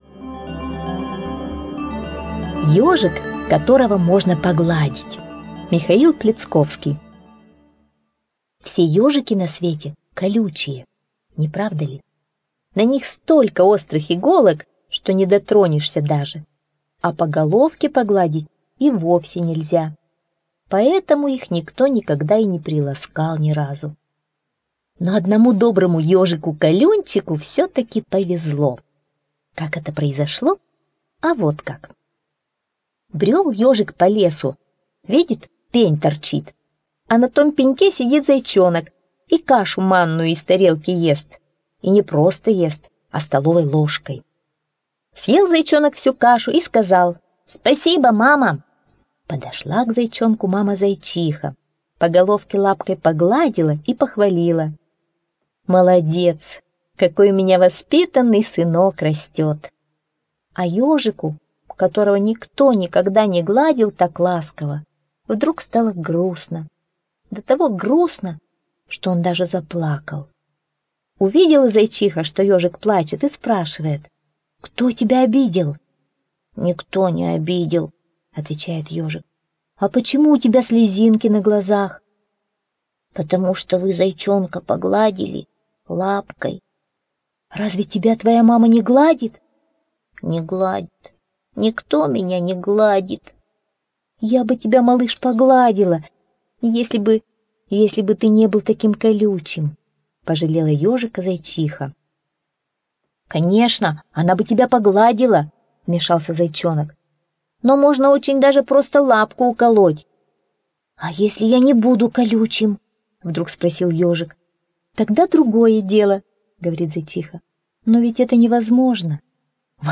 Ёжик, которого можно погладить - аудиосказка Пляцковского М.С. Слушать онлайн сказку про ёжика, который очень хотел, чтобы его погладили.